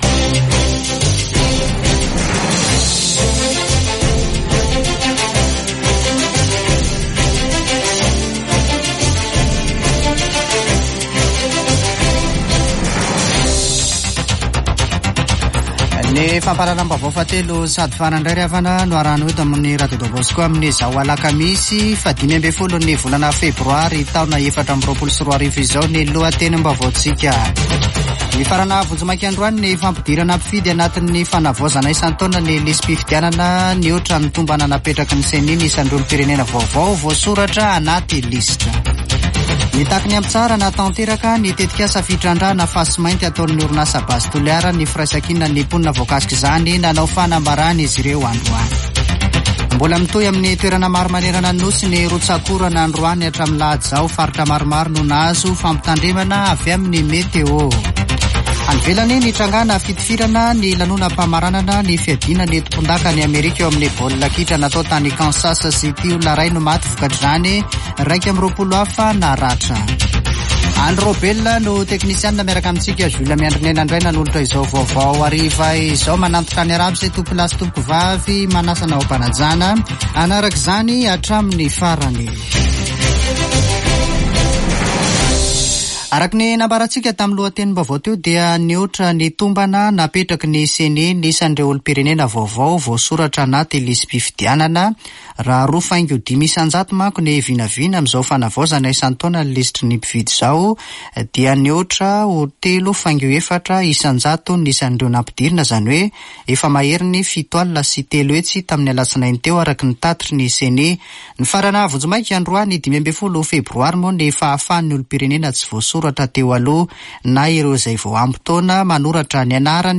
[Vaovao hariva] Alakamisy 15 febroary 2024